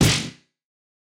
punch_ish_1.wav